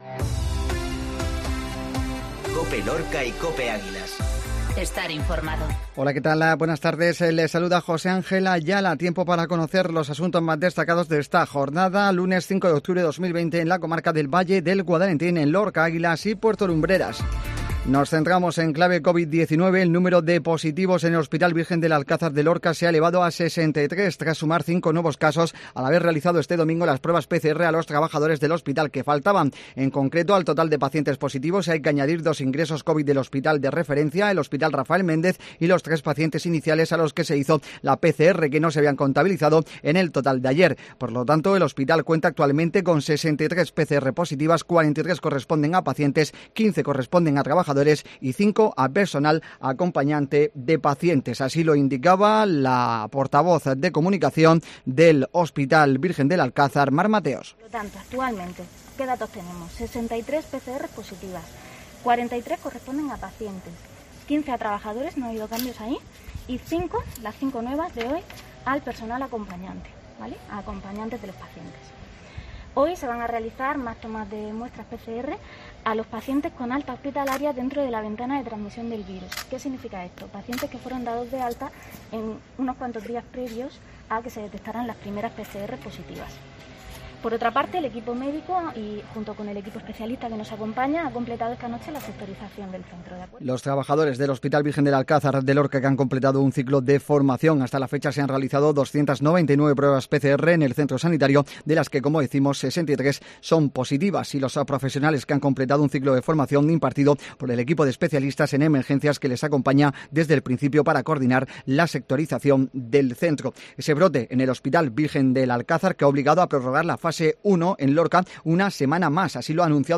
INFORMATIVO MEDIODÍA COPE LUNES 0510